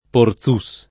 vai all'elenco alfabetico delle voci ingrandisci il carattere 100% rimpicciolisci il carattere stampa invia tramite posta elettronica codividi su Facebook Porzus [ por Z2S ] (friul. Porzûs [ por Z2 u S ]) top. (Friuli)